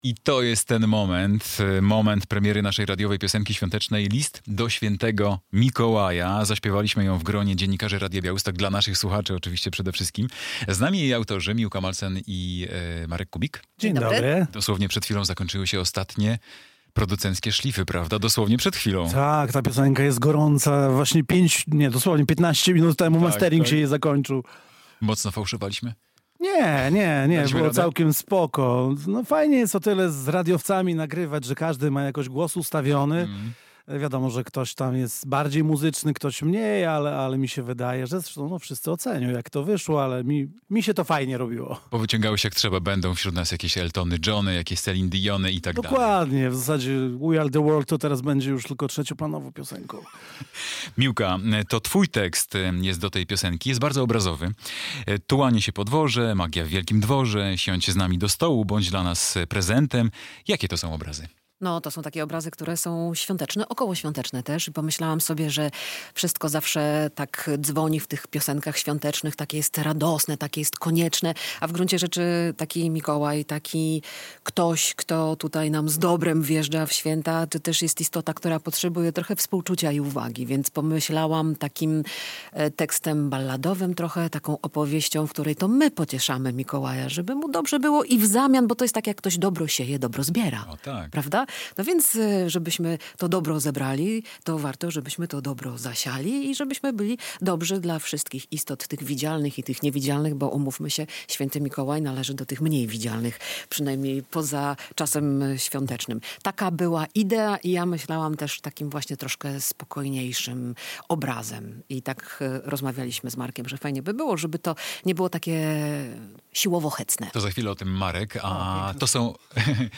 Nowa piosenka świąteczna, konkursy i Niebieski Mikołaj.